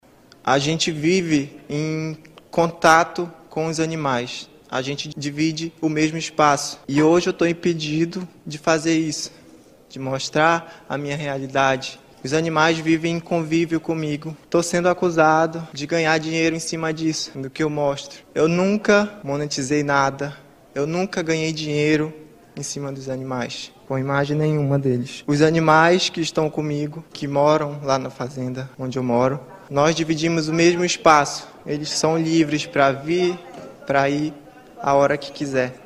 durante fala na Assembleia Legislativa do Amazonas